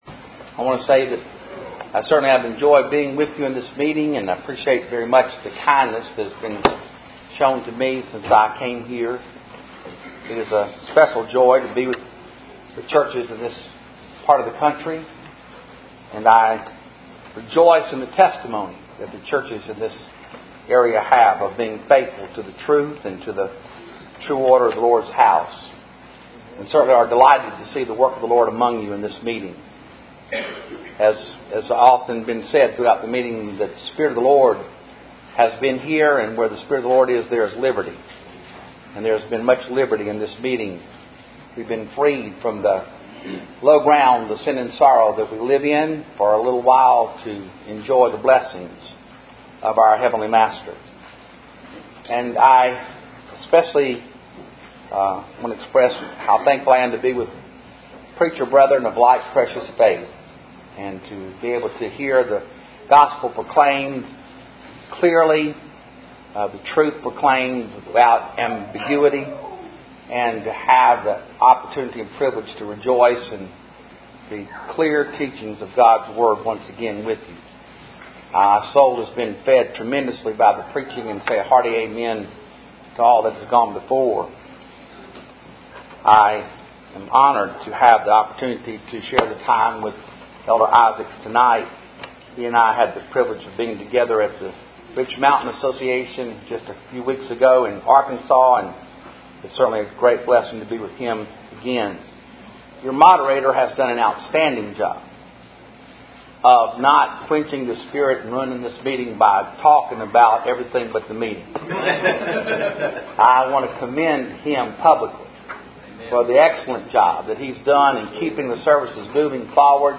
Passage: Philippians 3:13-14 Service Type: Ebenezer Fellowship Meeting